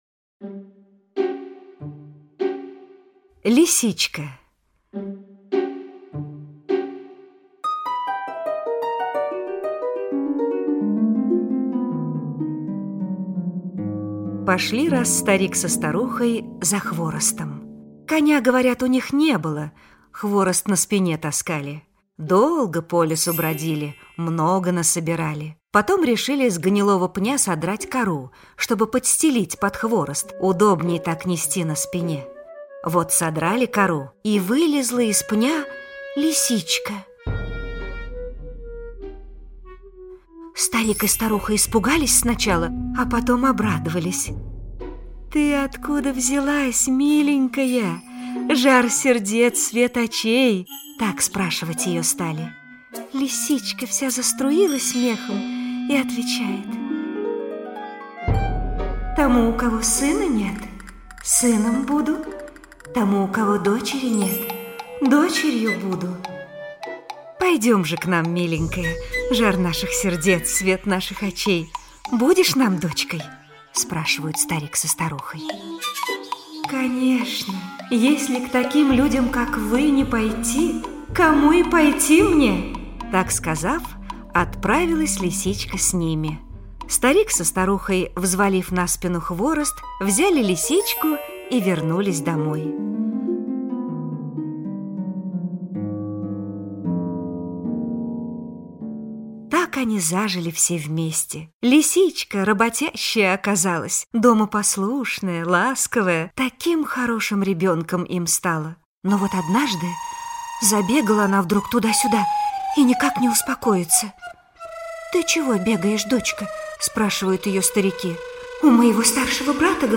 Лисичка - алтайская аудиосказка - слушать онлайн